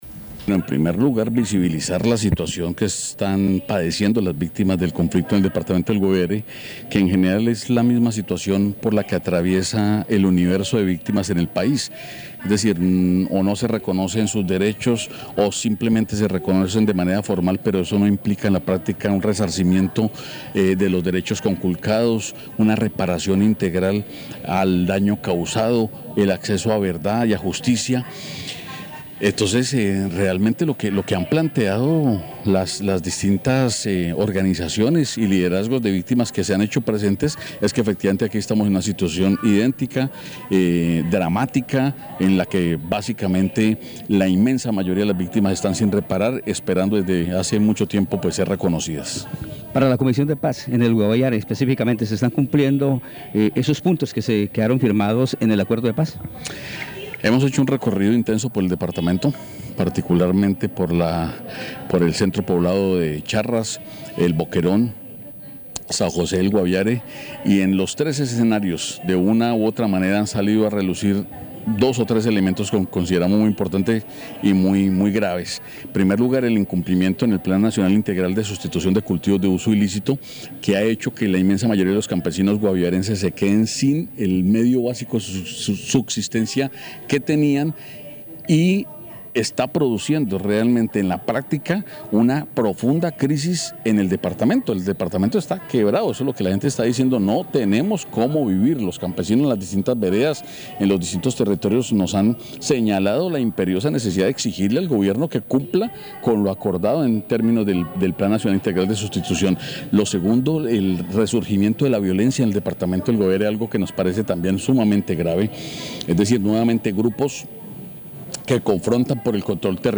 Escuche a Carlos Carreño, representante a la Cámara por el Partido Comunes.